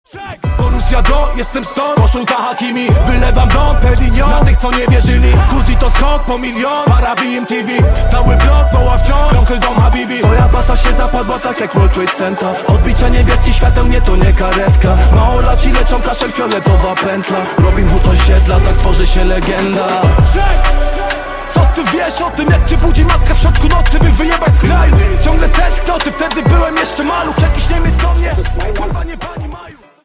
Hip-Hop/Rap